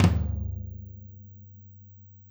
Tom Shard 04.wav